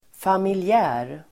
Ladda ner uttalet
Uttal: [familj'ä:r]